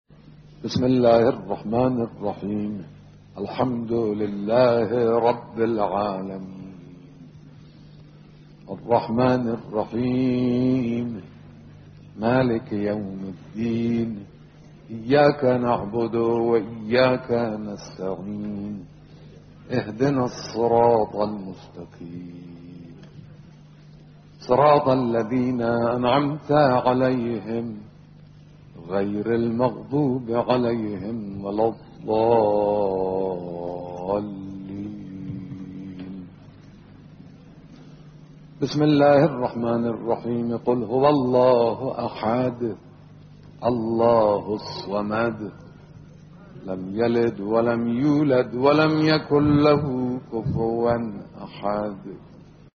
قرائت حمد و سوره با صدای رهبر انقلاب
قرائت قرآن با صدای آیت الله خامنه‌ای